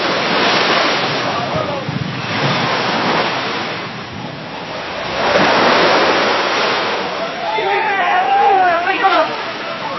The-Sound-Of-Surfing-In-The-Sea-And-Cheers.mp3